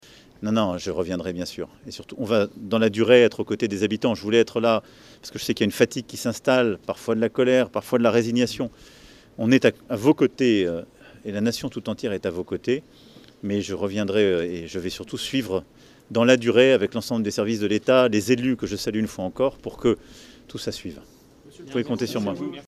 HP ILLUSTRATION Un Président qui a d’ailleurs promis au micro d’NRJ de revenir dans l’audomarois